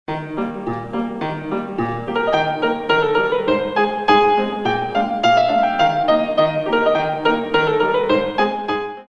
32 Counts